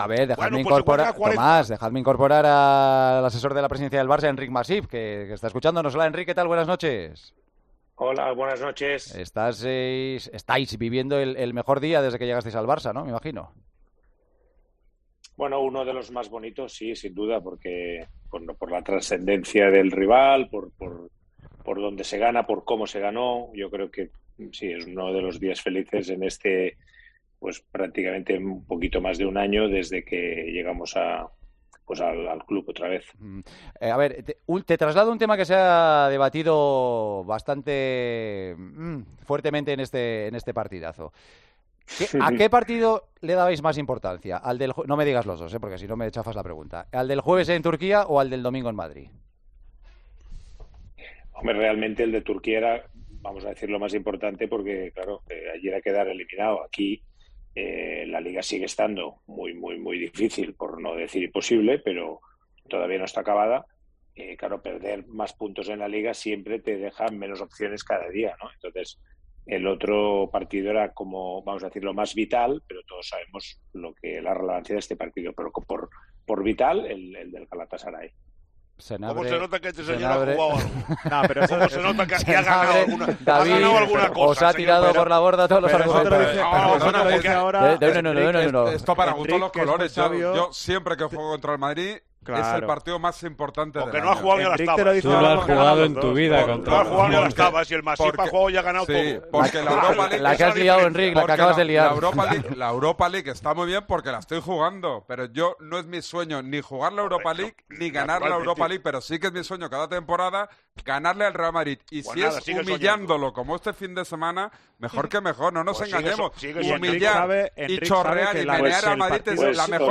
AUDIO - ENTREVISTA A ENRIC MASIP, EN EL PARTIDAZO DE COPE